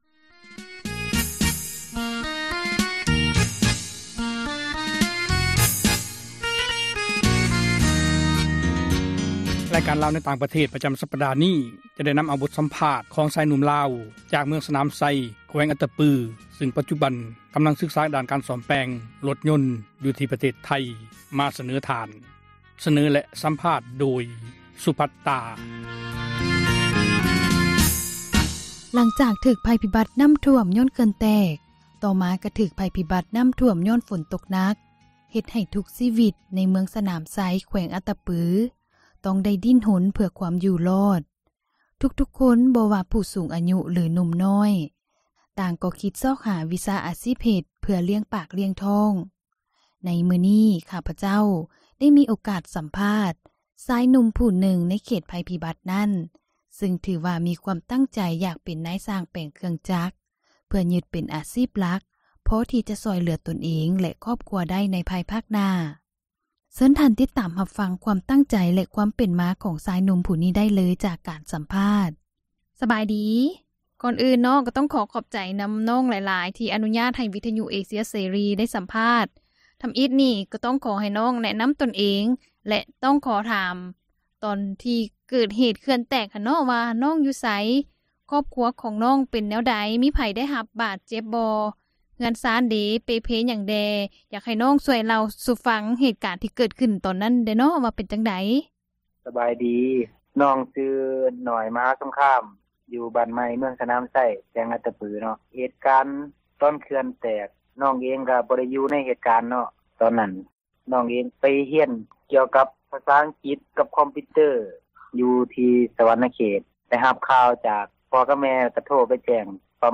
ສັມພາດ ຊາຍໜຸ່ມ ມ.ສນາມໄຊ
ວິທຍຸເອເຊັຽເສຣີ ໄດ້ມີໂອກາດ ໂອ້ລົມກັບ ຊາຍໜຸ່ມ ຢູ່ ເມືອງສນາມໄຊ ແຂວງອັດຕະປື ທີ່ ດີ້ນຮົນຊອກຫາ ທາງອອກ ໃຫ້ກັບຕົນເອງ, ຈຶ່ງຕັດສິນໃຈເລືອກ ວິຊາດ້ານສ້ອມແປງຣົດຍົນ ທີ່ ປະເທດໄທ ເພື່ອຍຶດເປັນ ອາຊີບ ໃນຂັ້ນຕໍ່ໄປ.